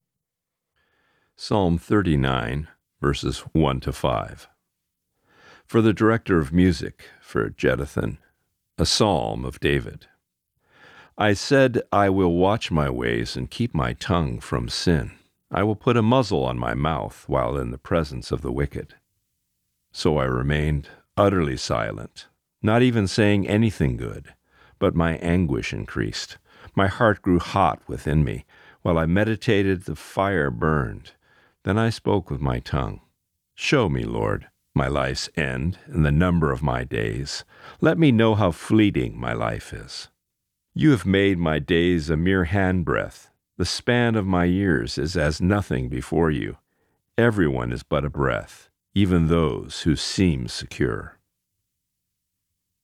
Reading: Psalm 39:1-5